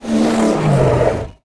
Index of /App/sound/monster/ice_snow_dog
dead_1.wav